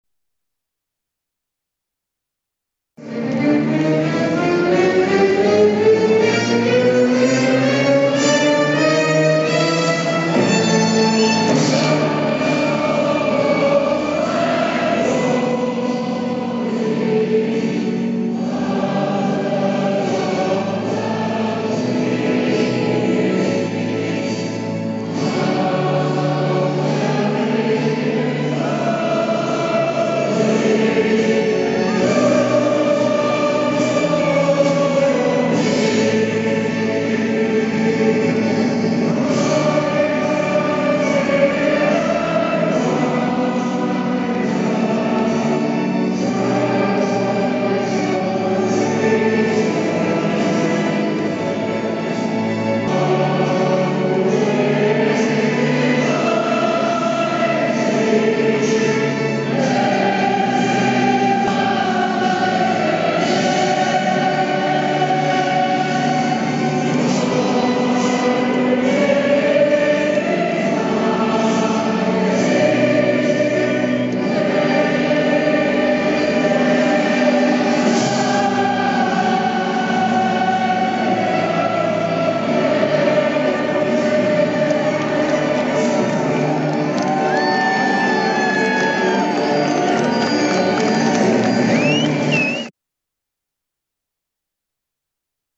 みなさん英国国旗やスコットランド国旗などを打ち振り、身にまとい、大合唱・大騒ぎします。
プロムス・ラストナイト最終盤。
最初は小雨。